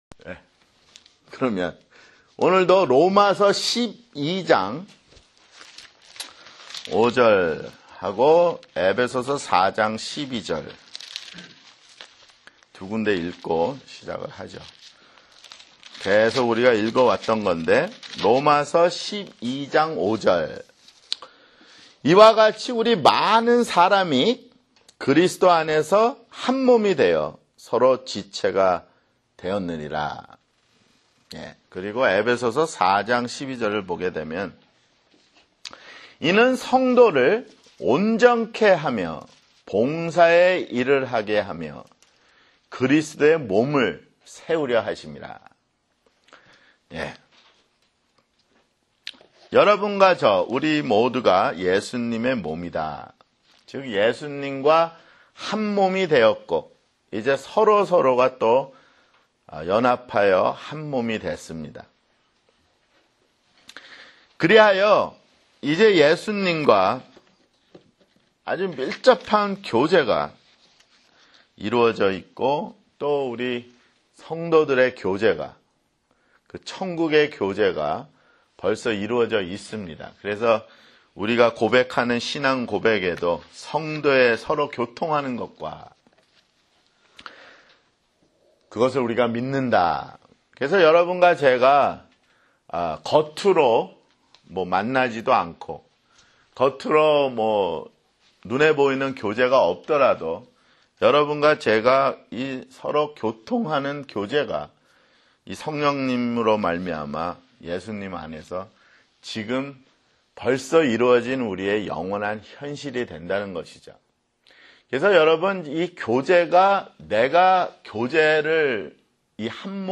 [성경공부] 교회 (14)